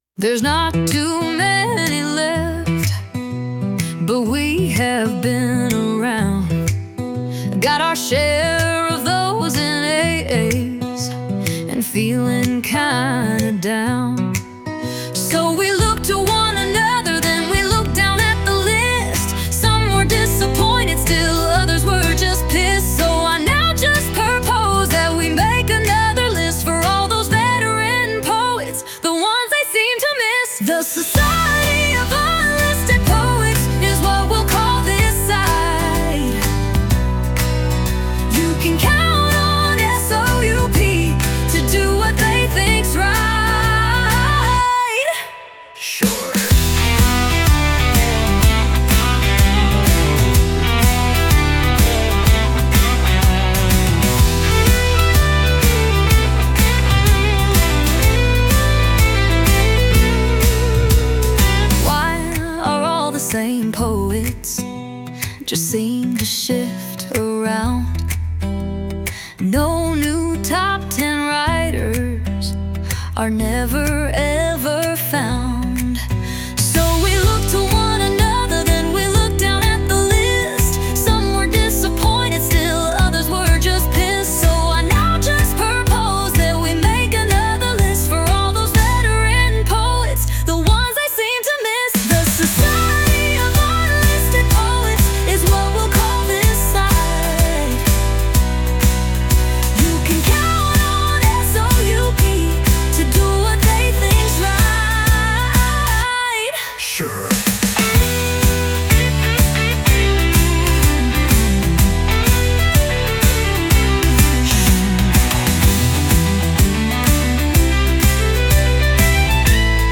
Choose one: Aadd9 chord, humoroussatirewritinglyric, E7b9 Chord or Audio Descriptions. humoroussatirewritinglyric